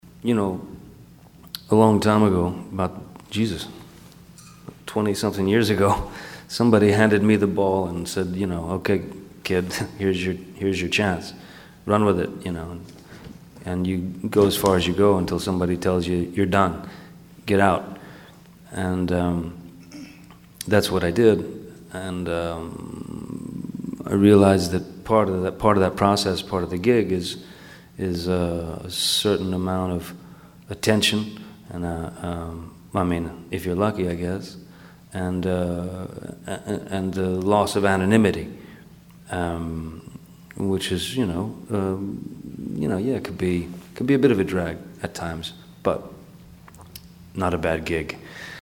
Public Enemies press junket